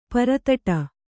♪ para taṭa